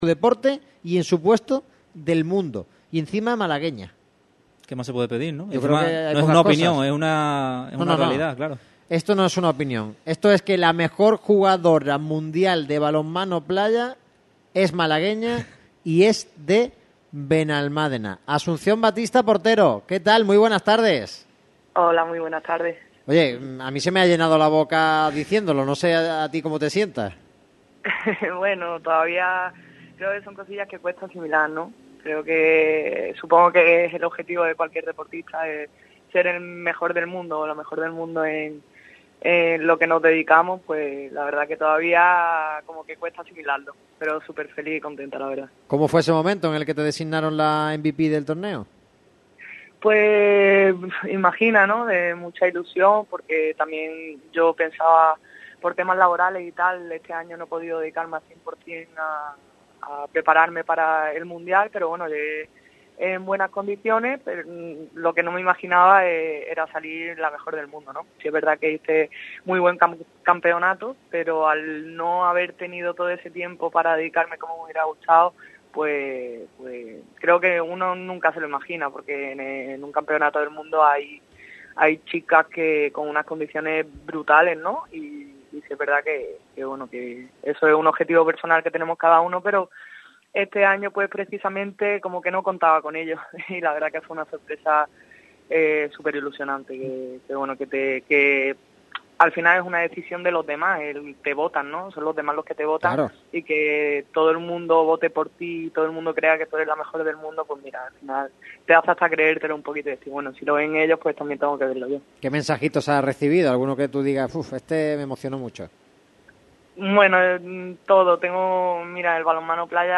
La reina del balonmano playa pasa por el micrófono rojo de Radio Marca Málaga